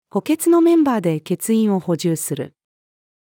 補欠のメンバーで欠員を補充する。-female.mp3